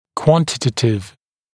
[‘kwɔntɪtətɪv][‘куонтитэтив]количественный